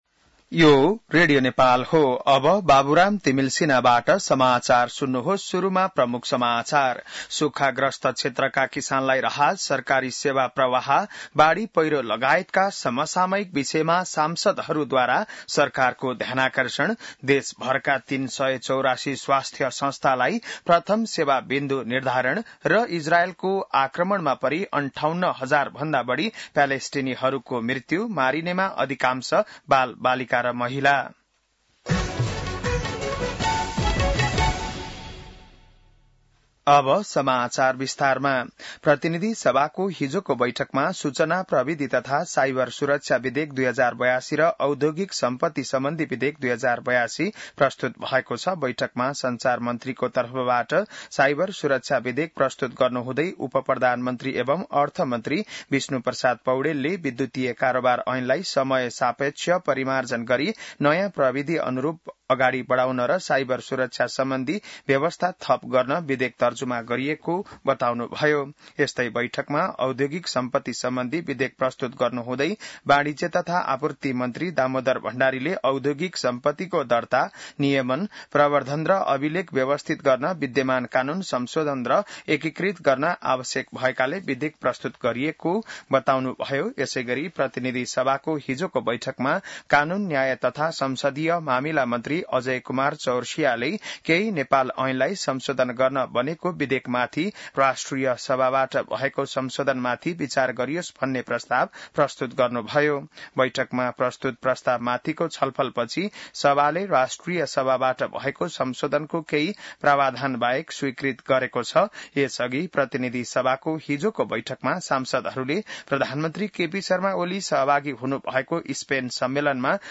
An online outlet of Nepal's national radio broadcaster
बिहान ९ बजेको नेपाली समाचार : ३० असार , २०८२